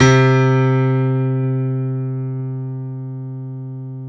SG1 PNO  C 2.wav